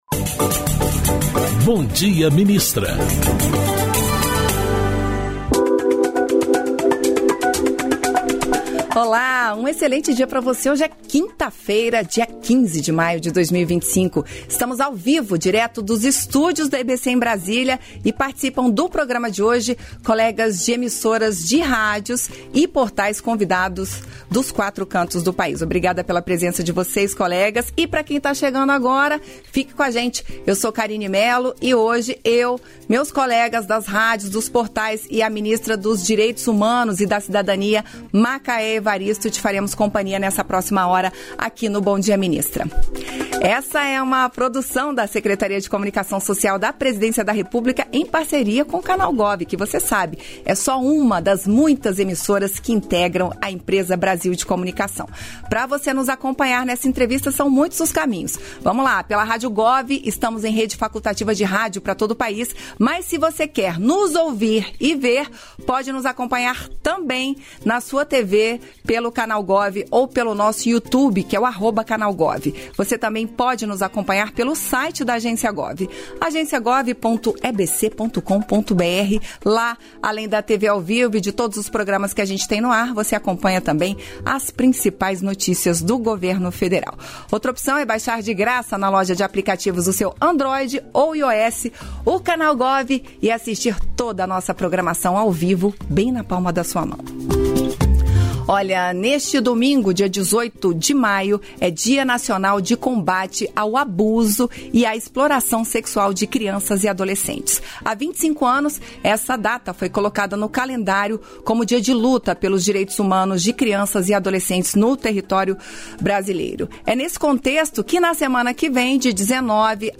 Íntegra da participação da ministra dos Direitos Humanos e da Cidadania, Macaé Evaristo, no programa "Bom Dia, Ministra" desta quinta-feira (15), nos estúdios da EBC em Brasília (DF).